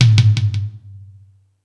Space Drums(29).wav